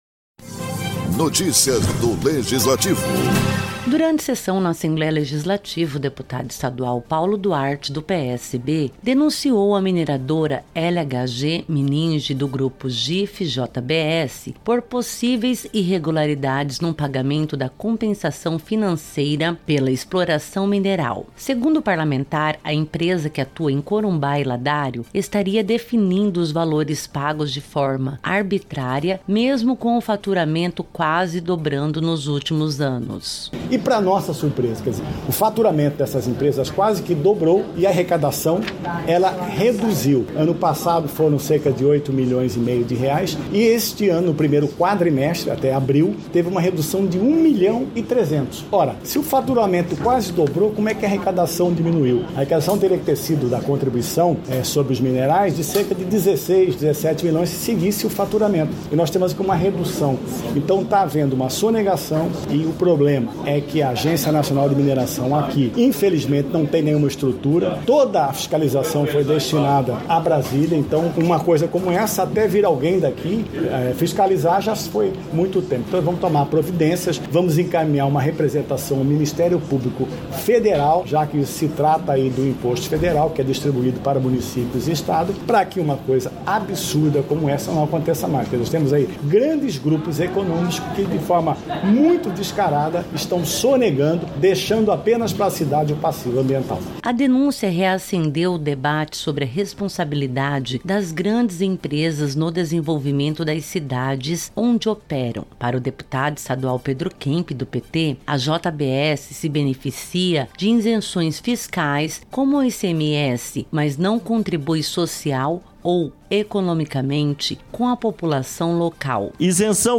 O deputado Paulo Duarte, do PSB, usou a tribuna para denunciar a LHG Mining, do grupo JIF/JBS, por irregularidades no pagamento da Compensação Financeira pela Exploração Mineral (CFEM). Segundo ele, a empresa, que atua em Corumbá e Ladário, define arbitrariamente os valores pagos, apesar de ter quase dobrado seu faturamento nos últimos anos.